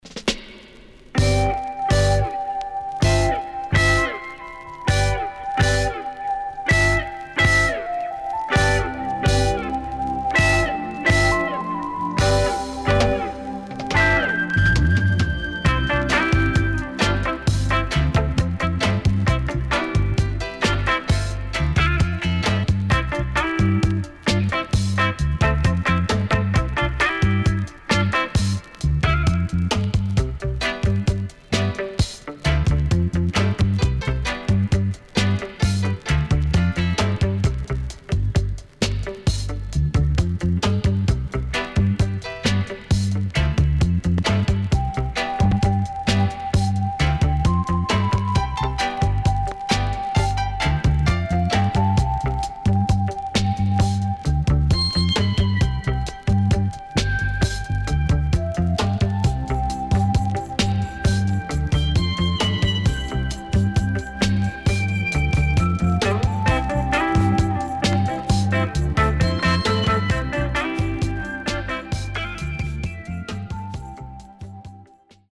【12inch】
SIDE A:所々チリノイズがあり、少しプチノイズ入ります。